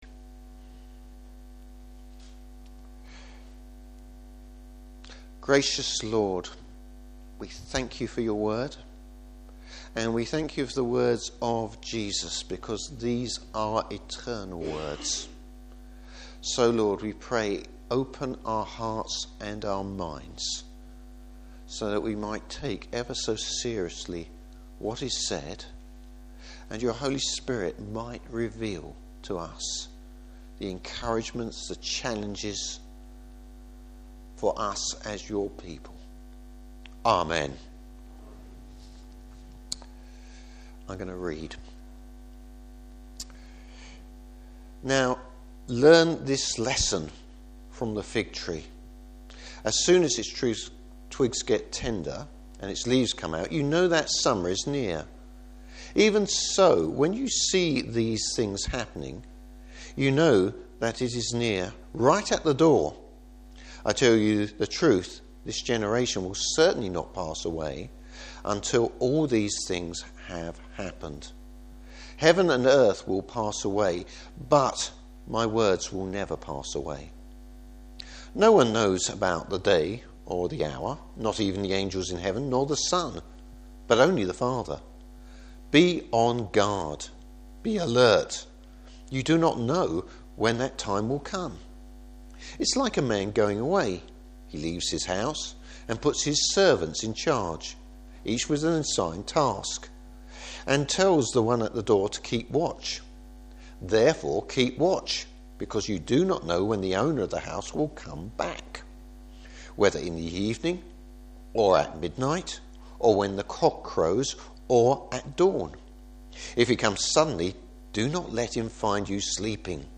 Service Type: Morning Service Instructions to the Church about future events.